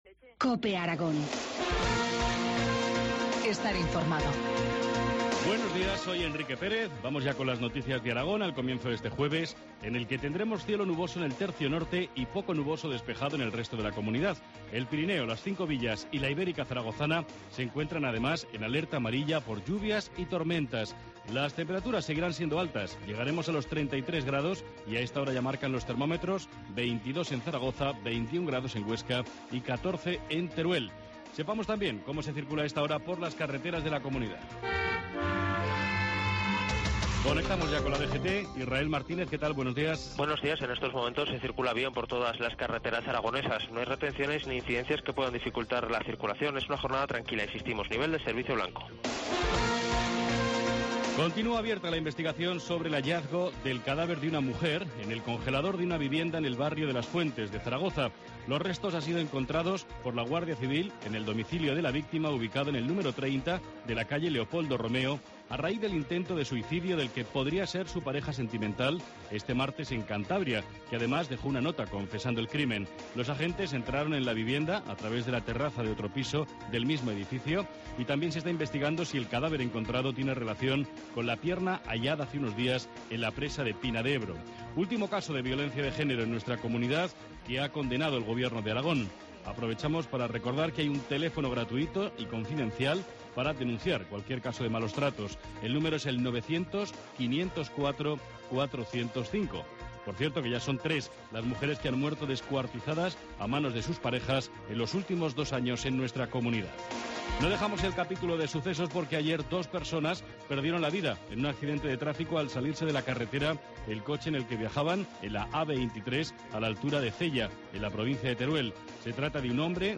Informativo matinal, jueves 13 de junio, 7.25 horas